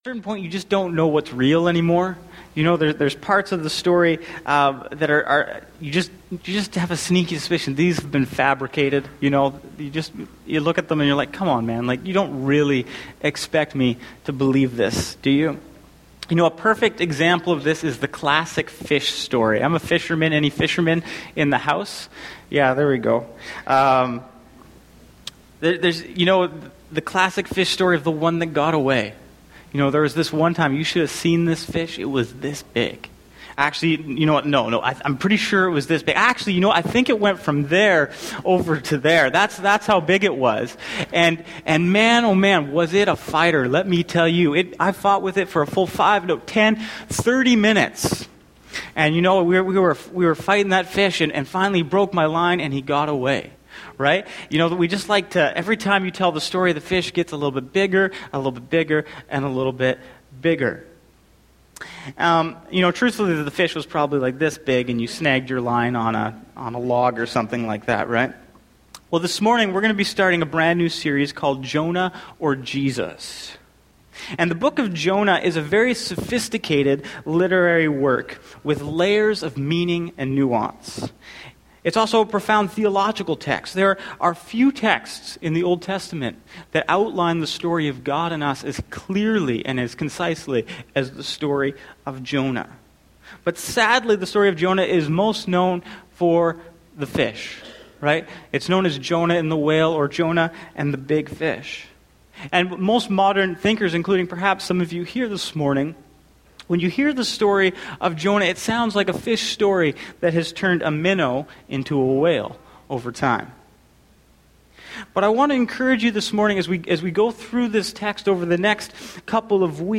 Sermons | Bethel Church Ladysmith